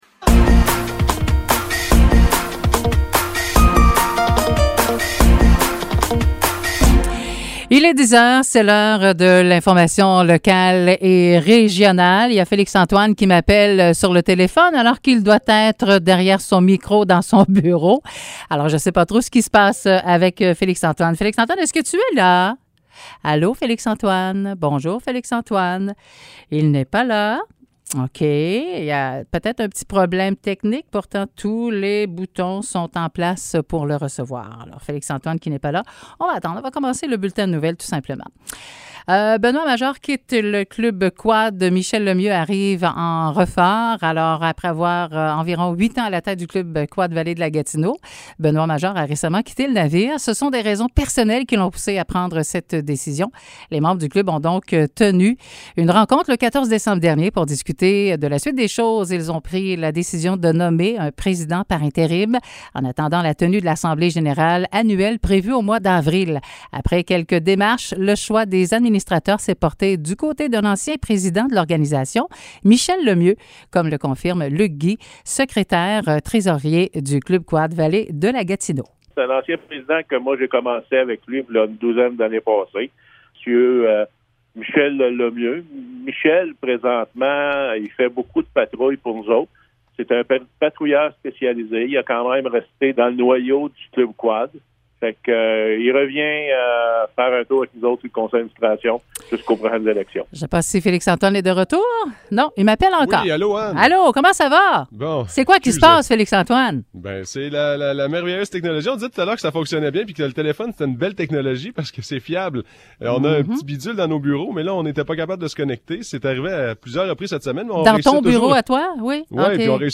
Nouvelles locales - 22 décembre 2022 - 10 h